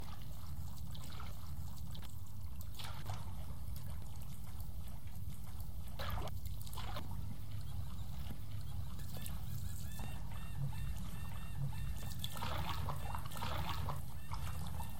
Ambiance Au port (Broadcast) – Le Studio JeeeP Prod
Bruits d’ambiance autour du port.
Ambiance-por15t.mp3